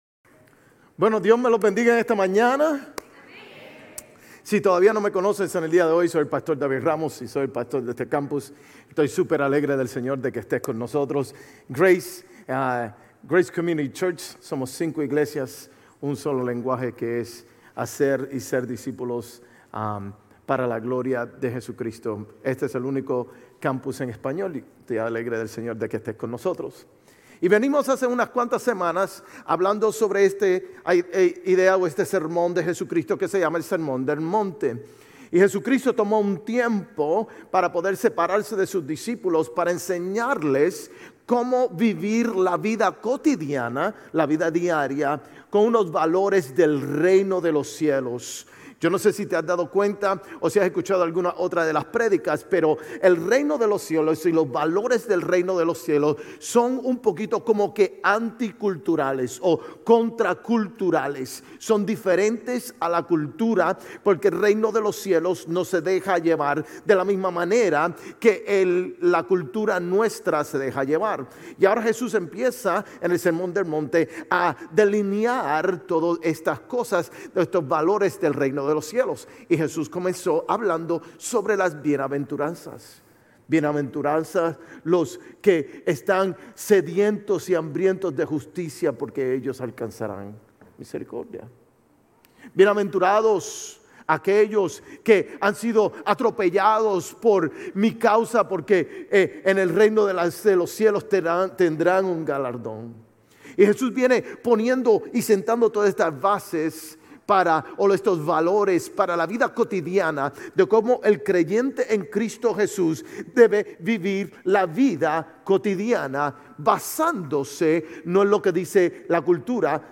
Sermones Grace Español 3_23 Grace Espanol Campus Mar 24 2025 | 00:37:42 1x 00:00 / 00:37:42 Subscribe Share RSS Feed Share Link Embed